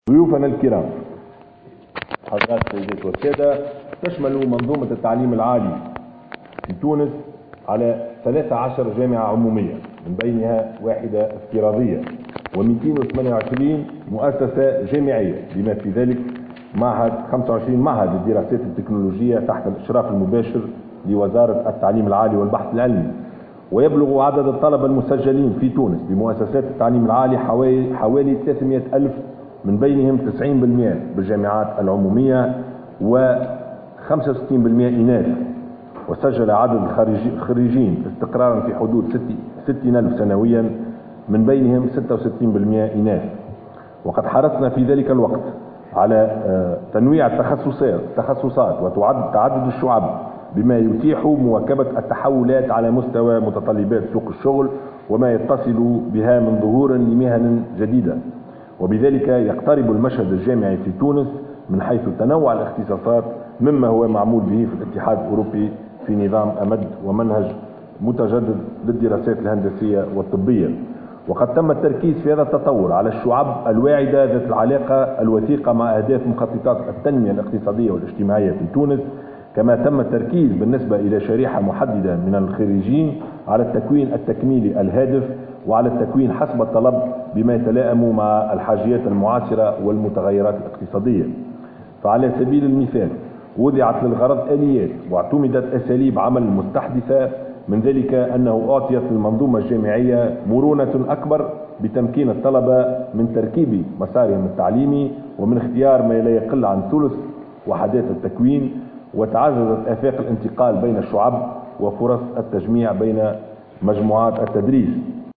تشمل منظومة التعليم العالي 13 جامعة عمومية و228 مؤسسة جامعية ويبلغ عدد الطلبة المسجلين حوالى 300 آلف من بينهم 90% بالجامعات العمومية 65 بالمائة منهم إناث، وفق ما ورد في كلمة رئيس الحكومة يوسف الشاهد في افتتاح المؤتمر الوزاري الثالث للبحث والتجديد والتعليم العالي لدول حوار 5 زائد 5.